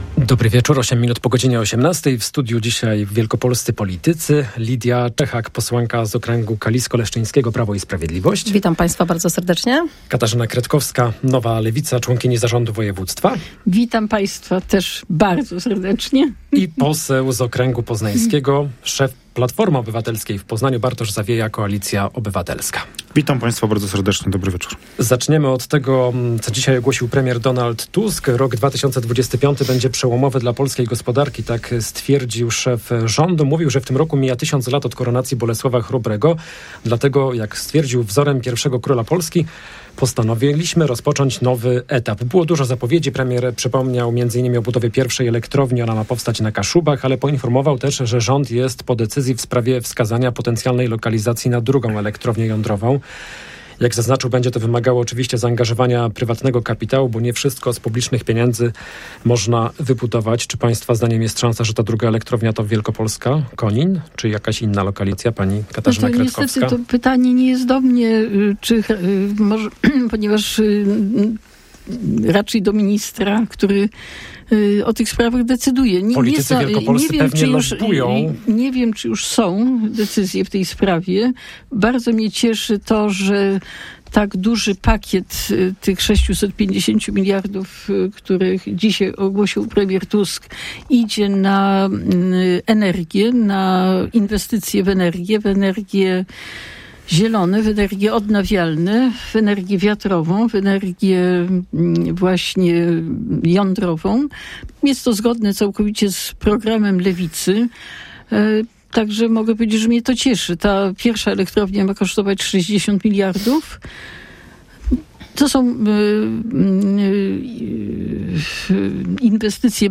O pobudzaniu gospodarki, śledztwie w sprawie zamachu stanu i pieniądzach na onkologię rozmawiają goście Rozmowy bardzo politycznej. Udział w programie biorą: Lidia Czechak (PIS), Katarzyna Kretkowska (Nowa Lewica) oraz Bartosz Zawieja (Platforma Obywatelska).